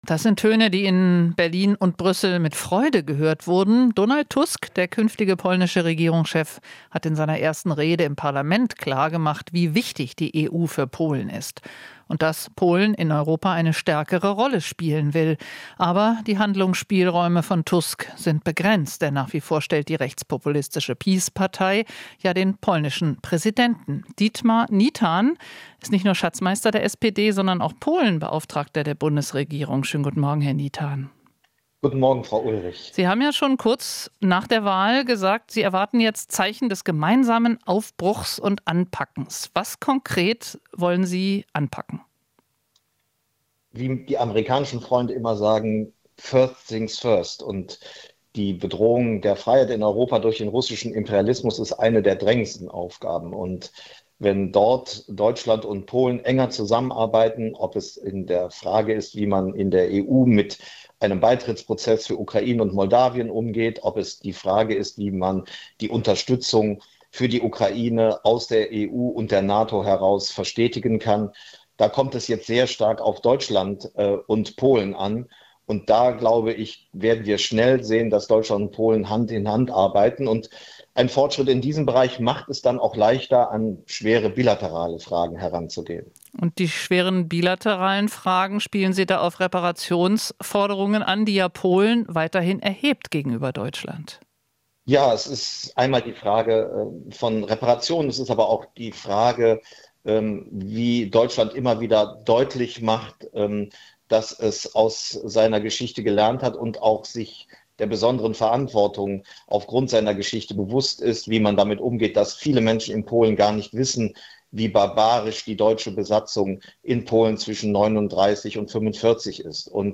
Interview - Polenbeauftragter Nietan (SPD): Wieder vertrauensvoll zusammenarbeiten
Das Interview haben wir am Morgen vor der Vereidigung von Donald Tusk geführt.